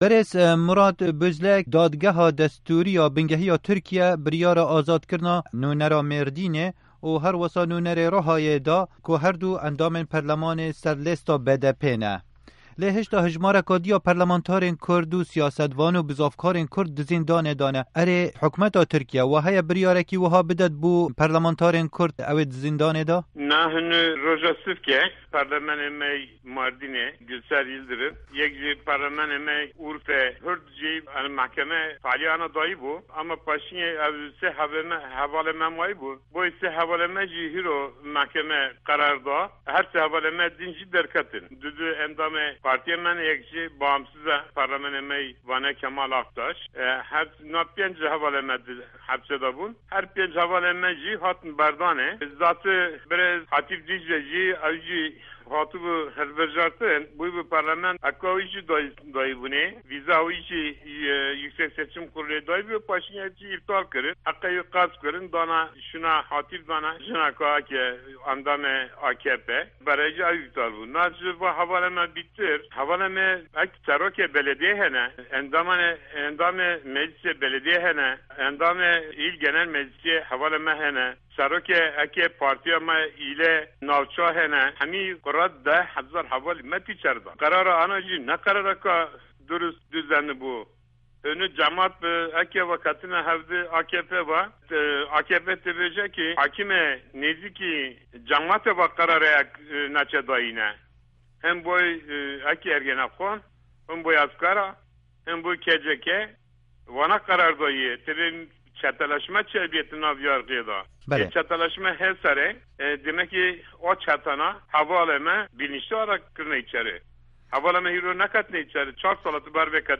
Hevpeyvîn bi Murat Bozlak re